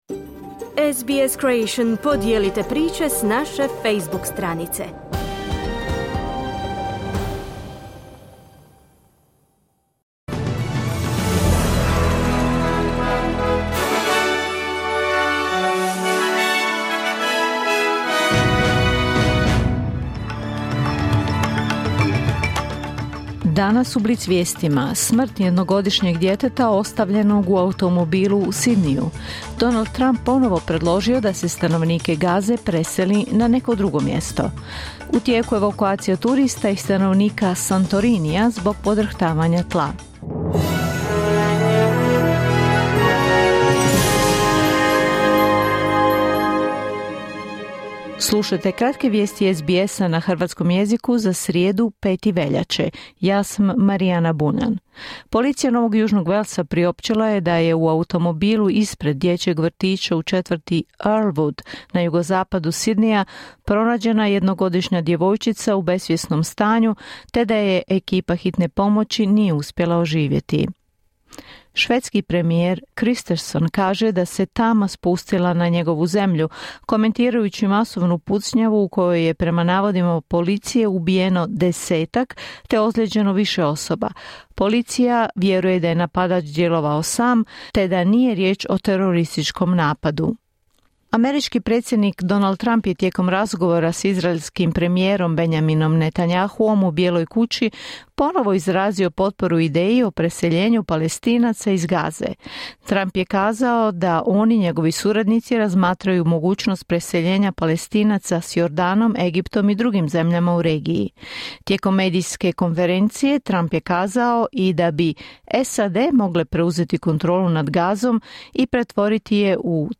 Vijesti radija SBS.